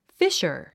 fissure 発音 fíʃə r フィッシャー